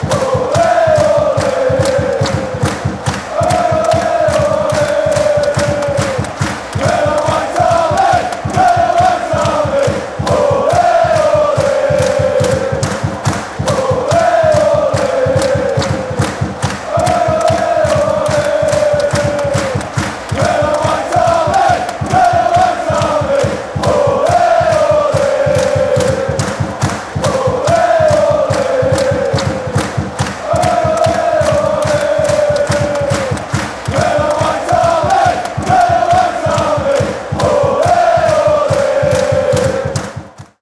Listen to 190 Middlesbrough FC football songs and Middlesbrough soccer chants from Riverside Stadium.